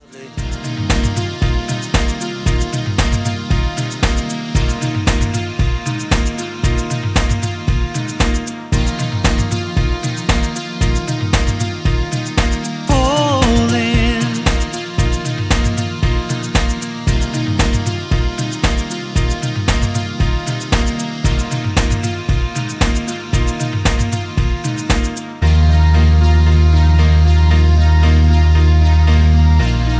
Backing track files: Duets (309)
Buy With Backing Vocals.